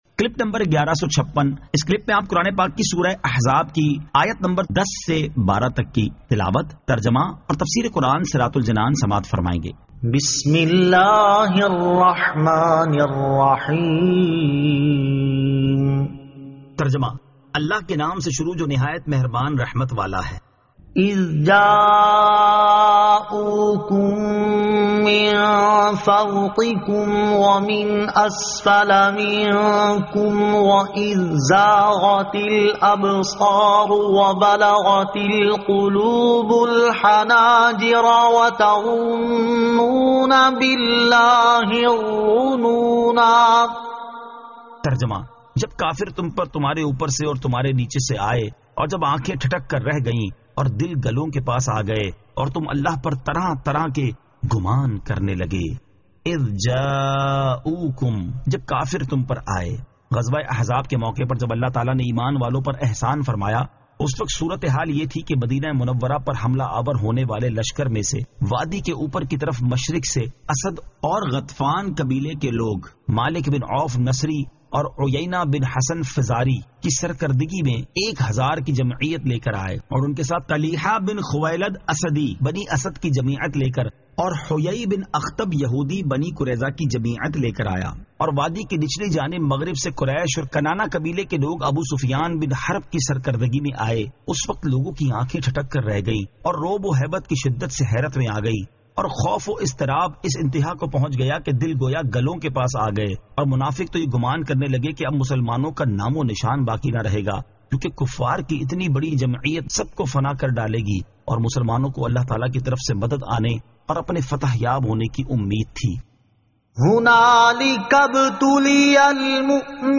Surah Al-Ahzab 10 To 12 Tilawat , Tarjama , Tafseer